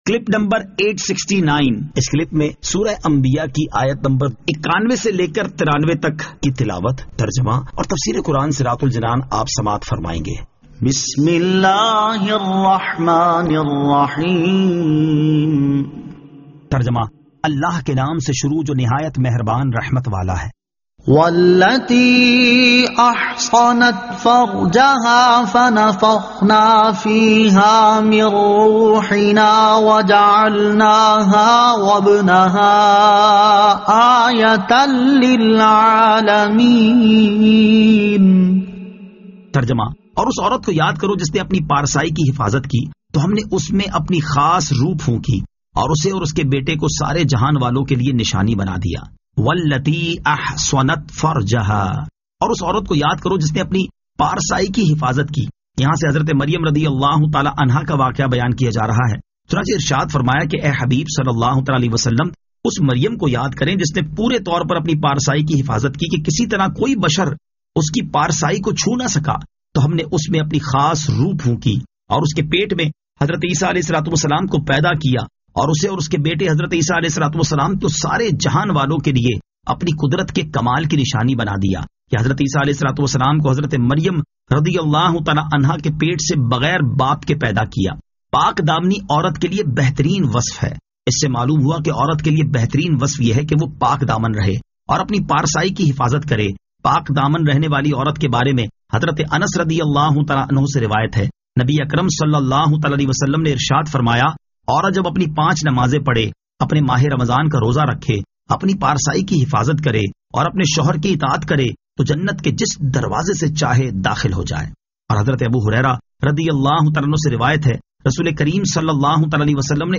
Surah Al-Anbiya 91 To 93 Tilawat , Tarjama , Tafseer